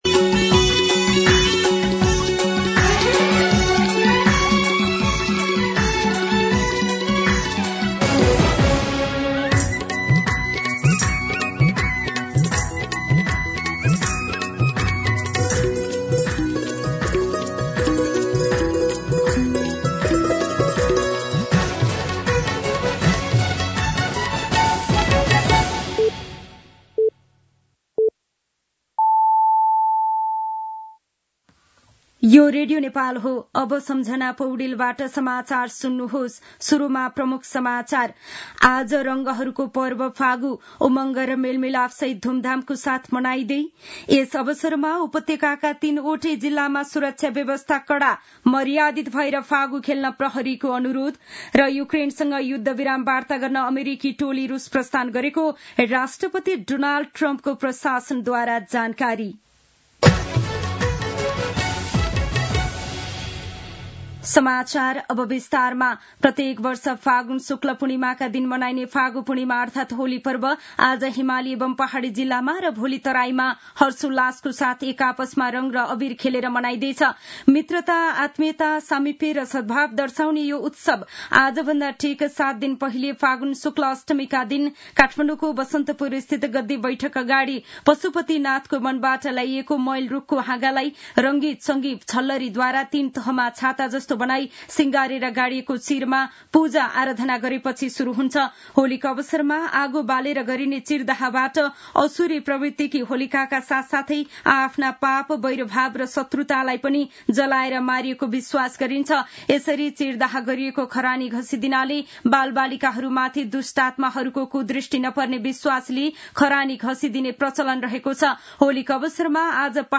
दिउँसो ३ बजेको नेपाली समाचार : ३० फागुन , २०८१
3-pm-news-1.mp3